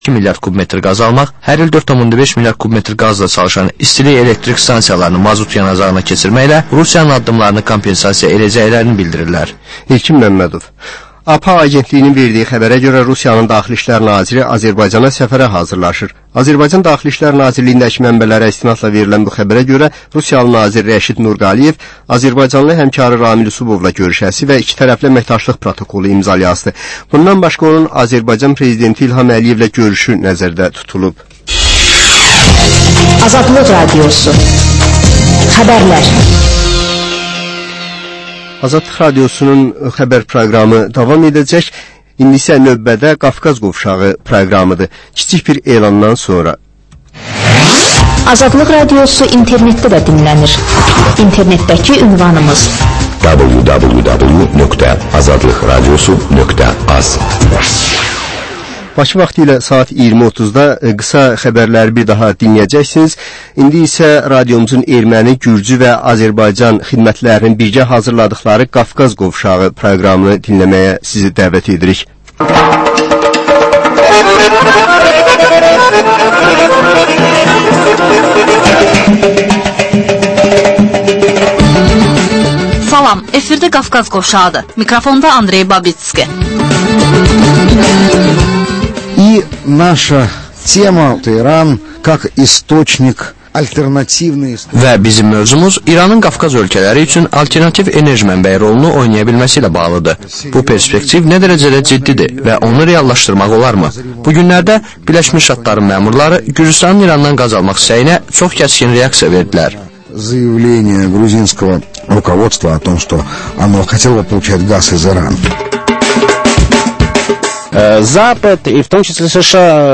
Azərbaycan, Gürcüstan və Ermənistandan reportajlar